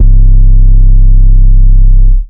808 2 {C} [ underground ].wav